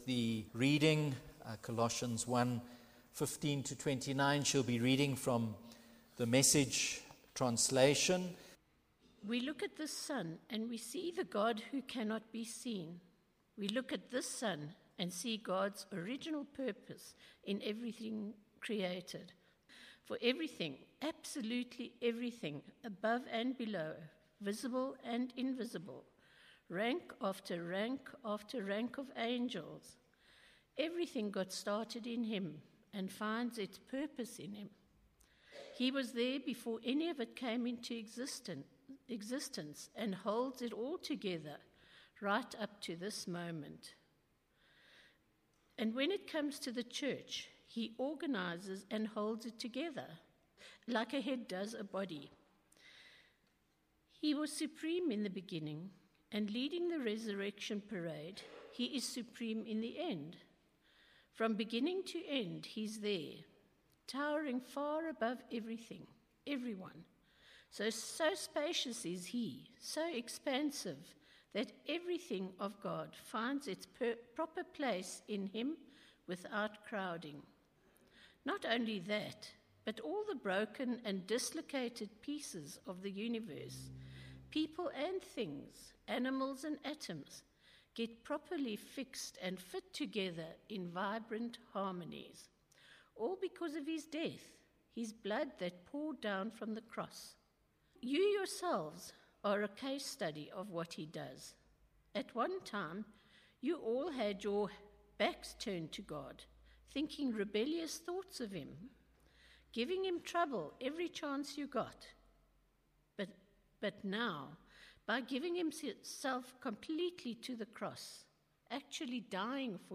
9:30am Fusion Service from Trinity Methodist Church, Linden, Johannesburg
Sermons